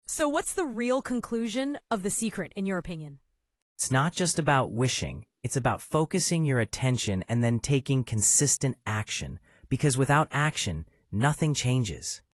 Dialogue: